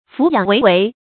俯仰唯唯 fǔ yǎng wéi wéi
俯仰唯唯发音